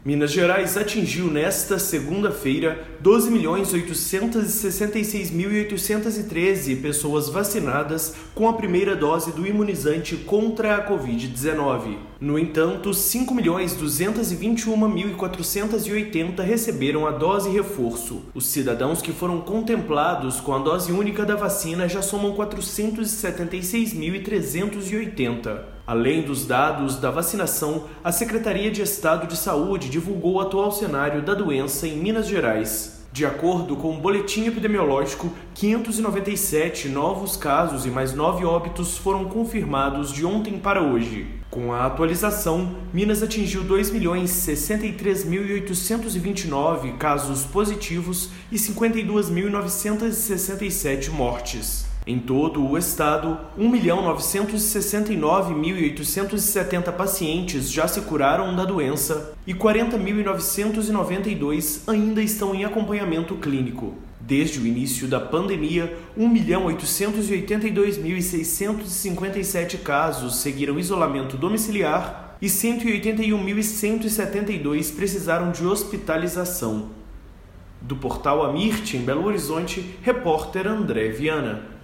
Boletim: 12,8 milhões cidadãos já receberam a primeira dose da vacina contra a Covid-19